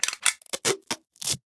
repair5.ogg